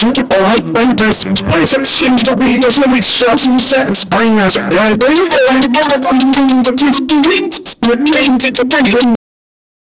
os-dois-sinos-dos-bumps-7v6p43dq.wav